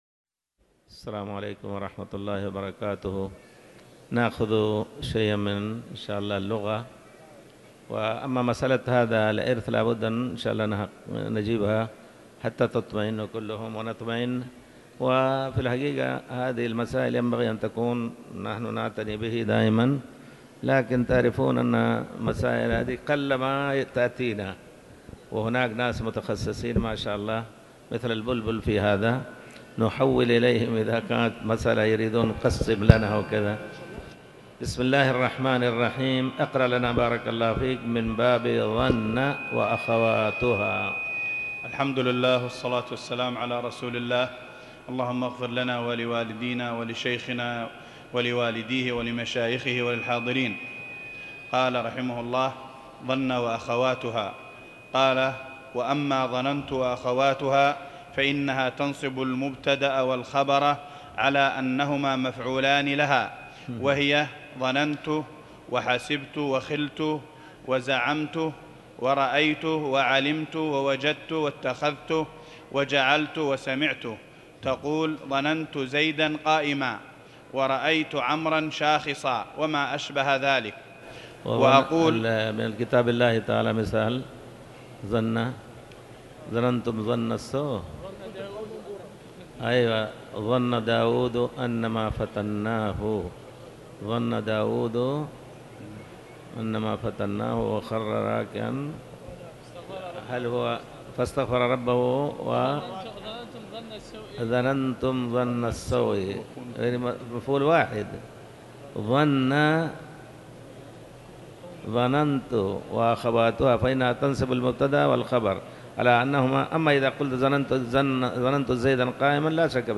تاريخ النشر ٢ ربيع الأول ١٤٤٠ هـ المكان: المسجد الحرام الشيخ